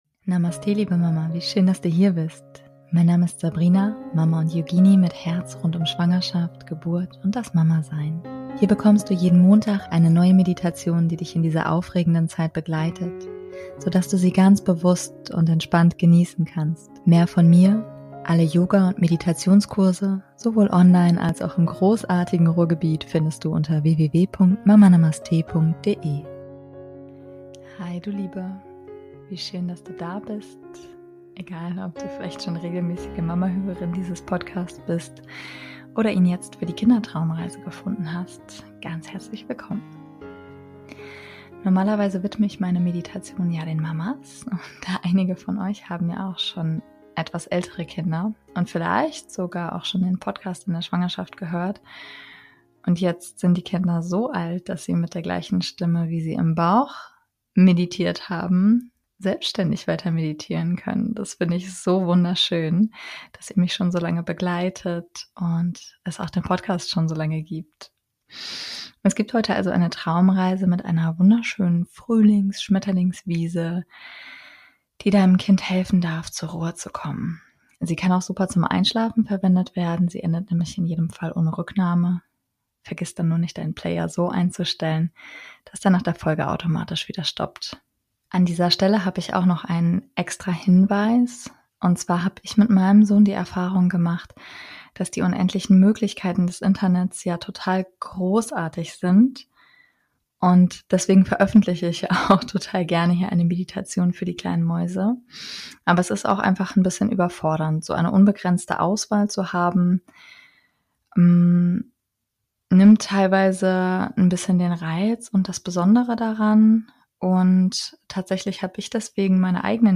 Es gibt also heute eine Traumreise, mit einer wunderschönen Frühlings Schmetterlings Wiese, die deinem Kind helfen darf zur Ruhe zu kommen. Sie kann auch super zum Einschlafen verwendet werden. Sie endet nämlich in jedem Fall ohne Rücknahme.